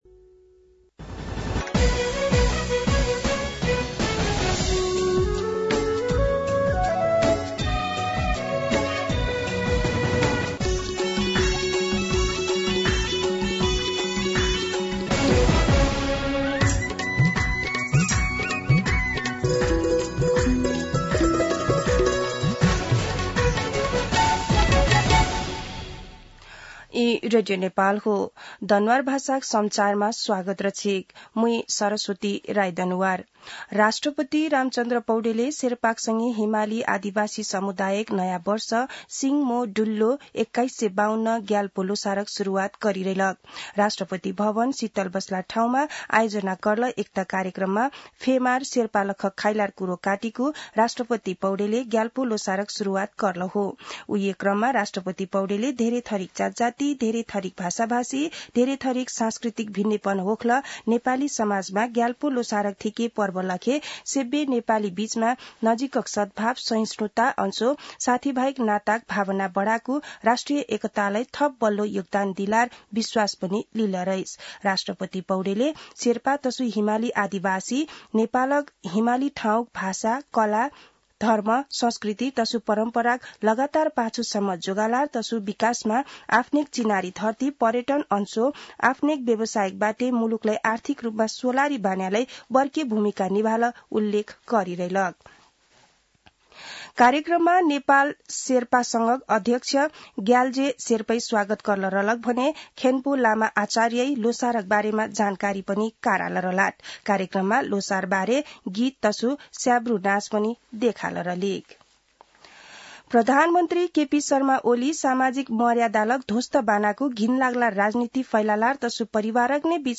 दनुवार भाषामा समाचार : १६ फागुन , २०८१
Danuwar-News-11-15.mp3